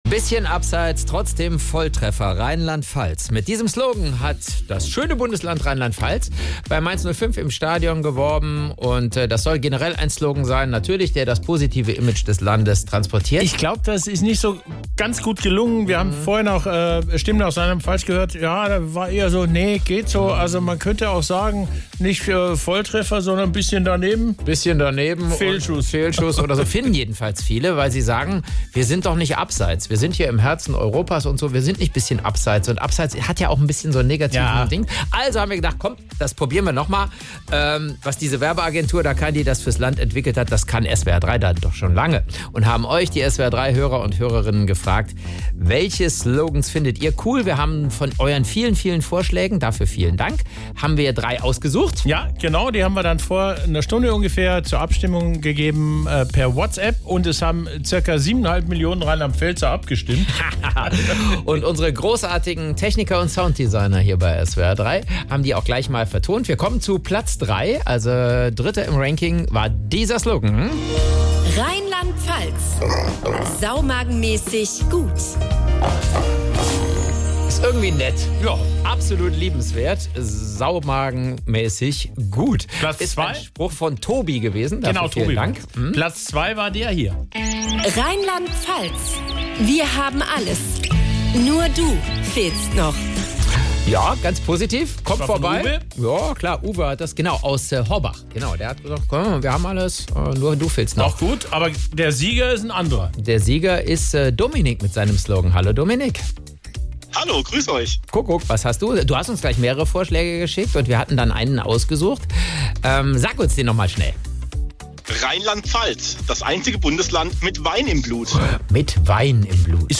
im Interview.